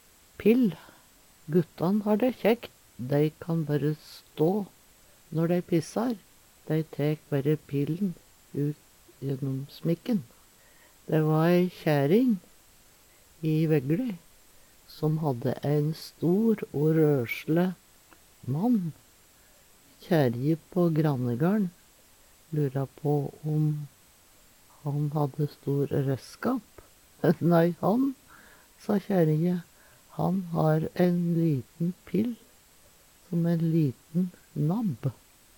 pill - Numedalsmål (en-US)